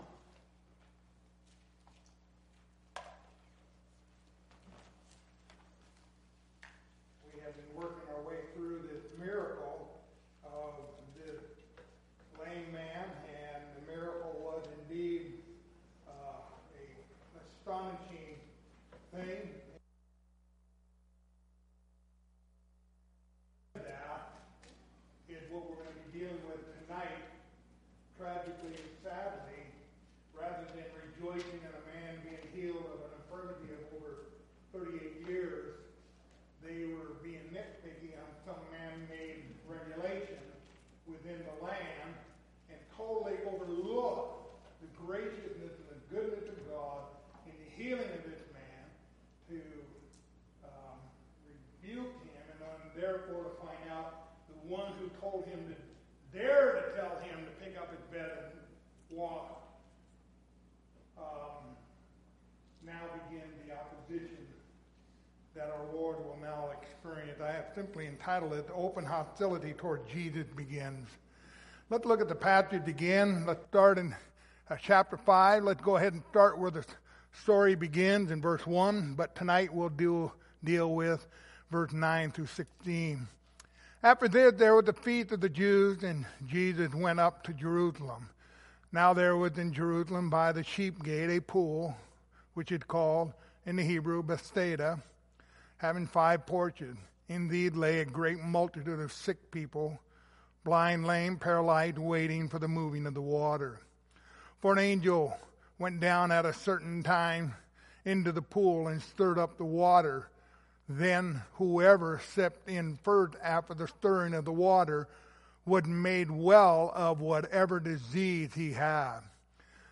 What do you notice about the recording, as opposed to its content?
Passage: John 5:9-16 Service Type: Wednesday Evening